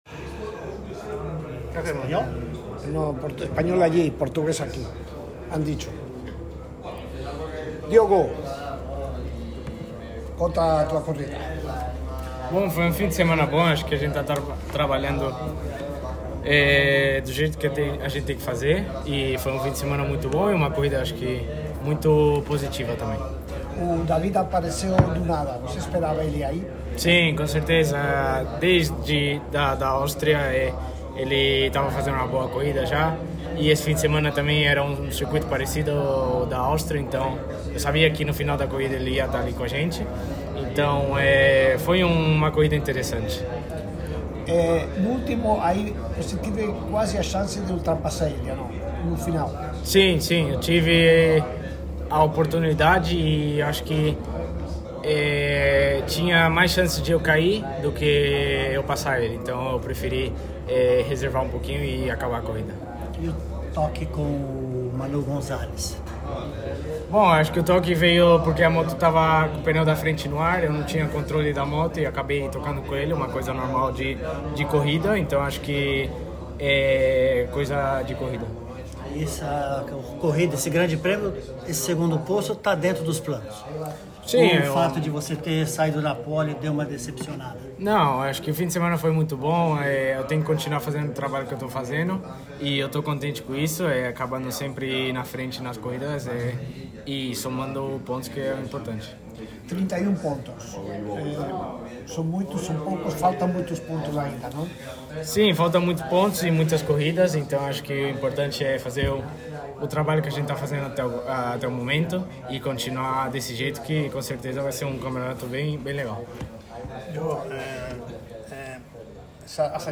“Segui a estratégia para a prova e foi um ótimo resultado, faltam oito provas e continuaremos lutando” disse o brasileiro que agora está a 31 pontos atrás do líder do campeonato Manu Gonzalez. O áudio da entrevista encontra-se no final dessa matéria.
entrevista.mp3